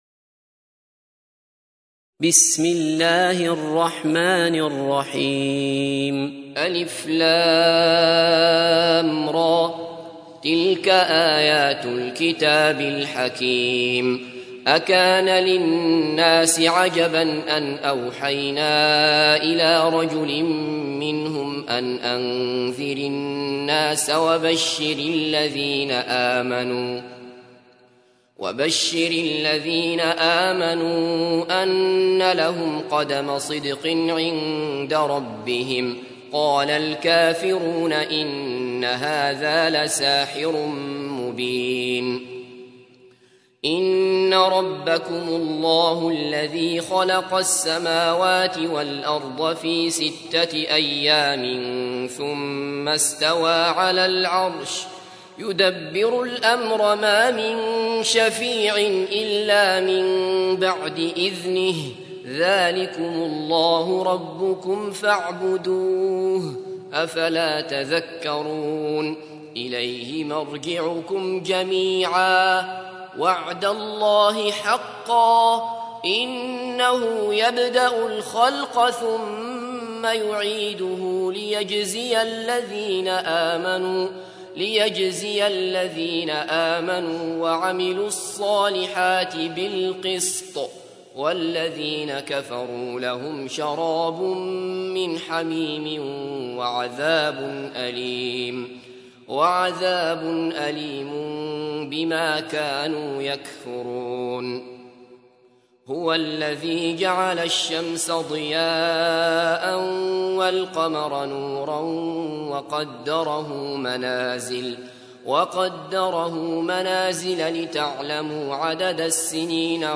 تحميل : 10. سورة يونس / القارئ عبد الله بصفر / القرآن الكريم / موقع يا حسين